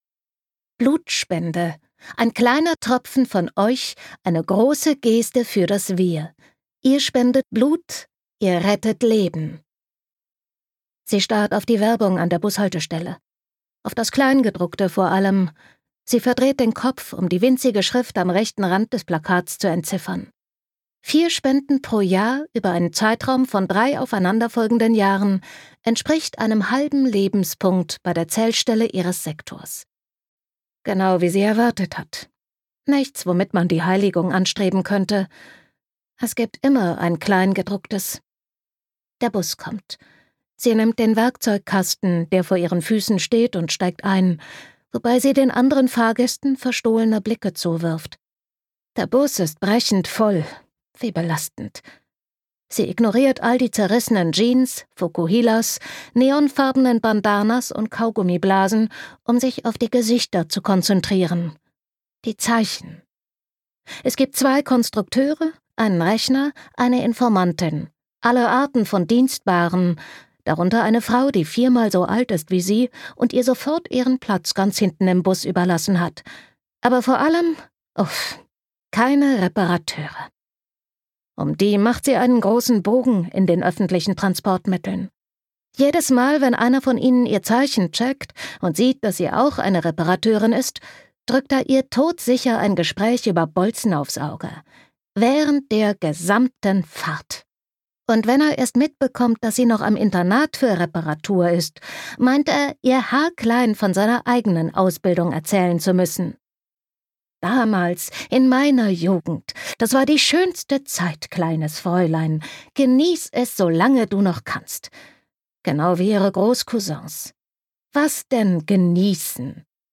Gekürzt Autorisierte, d.h. von Autor:innen und / oder Verlagen freigegebene, bearbeitete Fassung.
Switch Studio, Berlin, 2022/ argon verlag